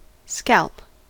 scalp: Wikimedia Commons US English Pronunciations
En-us-scalp.WAV